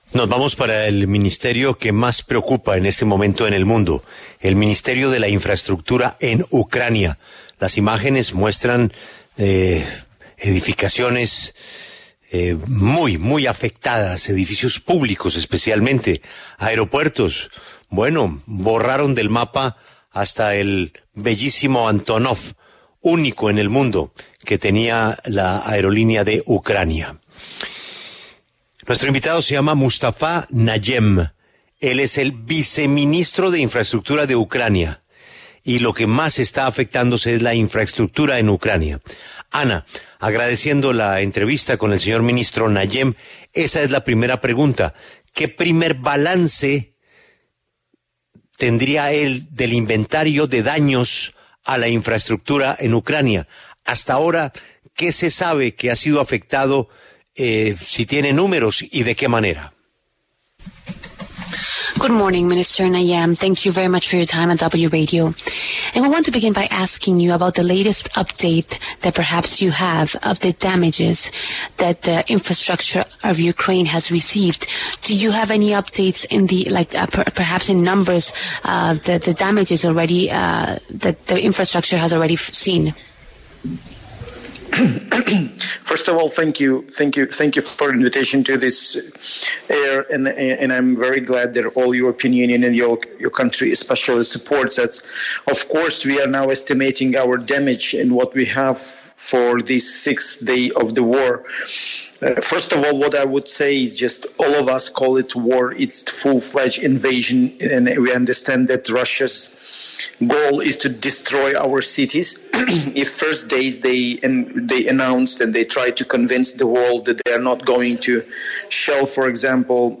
En el encabezado escuche la entrevista completa con Mustafa Nayem, viceministro de Infraestructura de Ucrania.